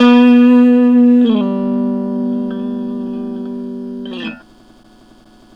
Track 02 - Guitar Lick 04.wav